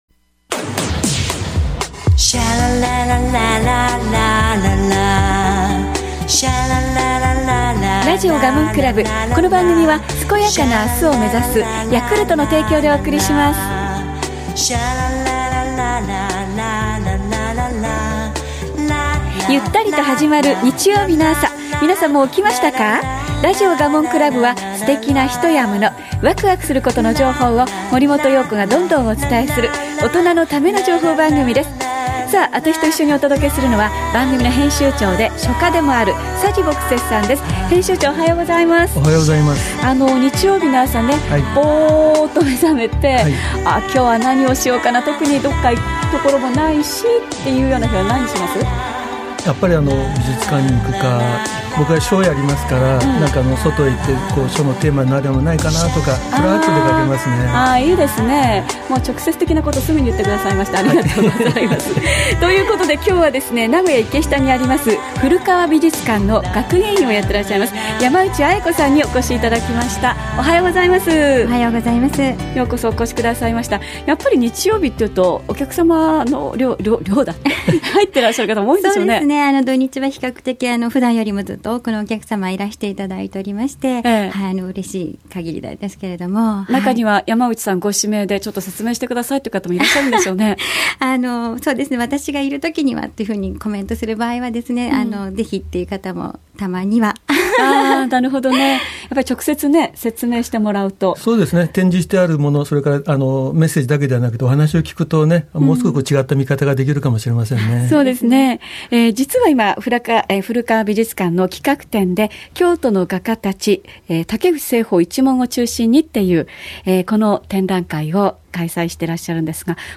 東海ラジオ（1332kHz）「らじおガモン倶楽部」へ担当学芸員出演、収録に伺いました